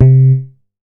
MoogLoRess 006.WAV